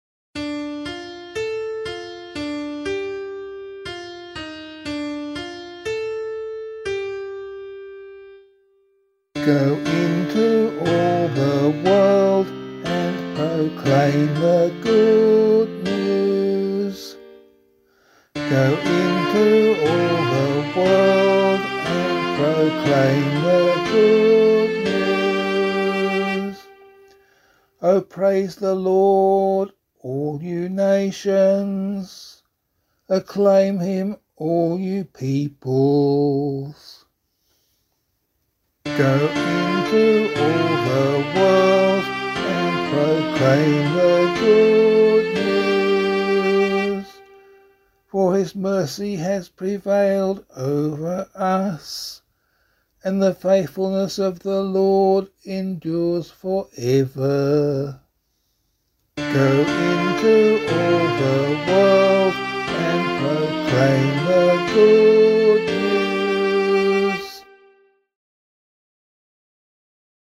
176 Patrick Psalm [APC - LiturgyShare + Meinrad 4] - vocal.mp3